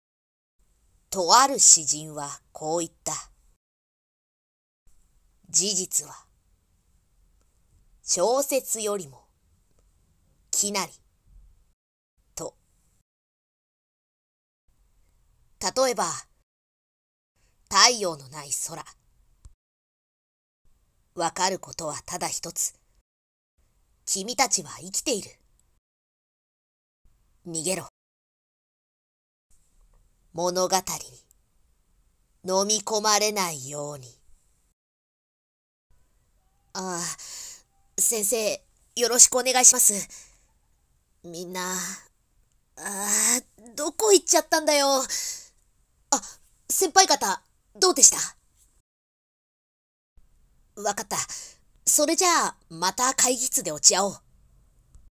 】🐦‍🔥声劇セリフ nanaRepeat